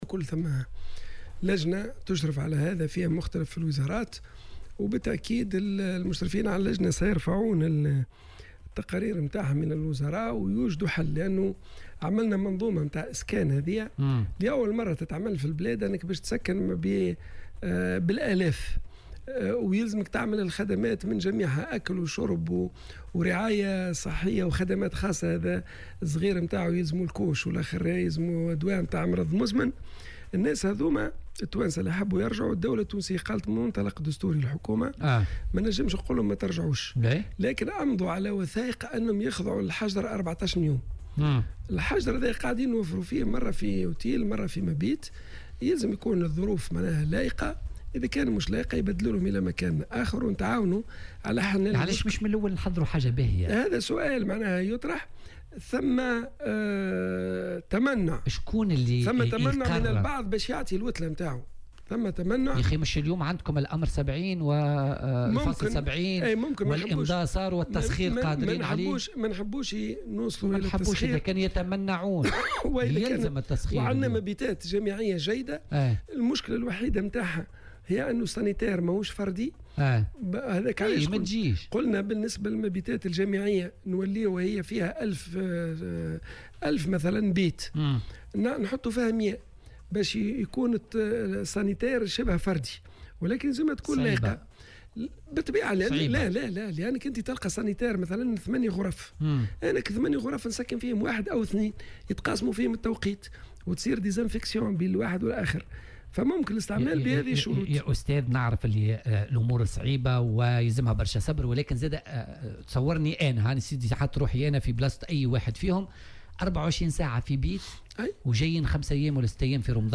قال وزير الصحة، عبد اللطيف المكي في مداخلة له اليوم في برنامج "بوليتيكا" إن بعض أصحاب النزل امتنعوا عن إيواء الخاضعين للحجر الصحي، الذين عادوا من الخارج.